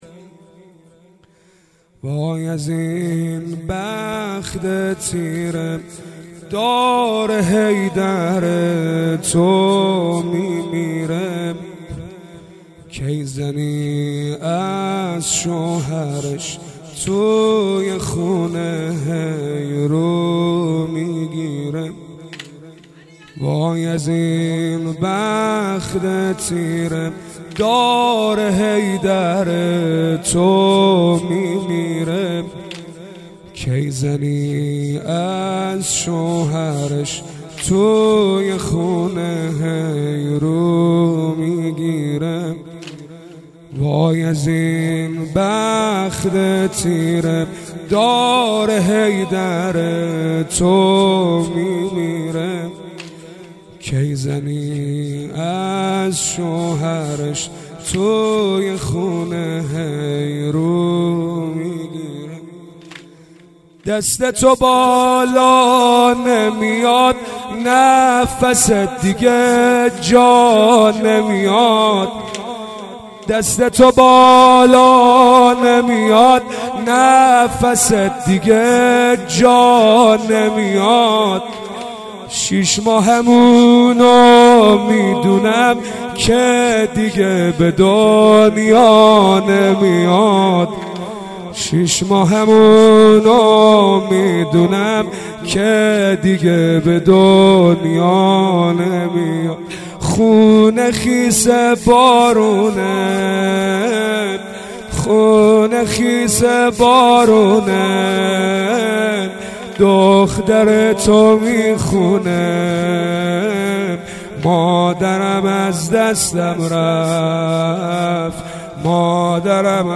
واي از اين بخت تيره ... واحد سنگين ...
• مداحی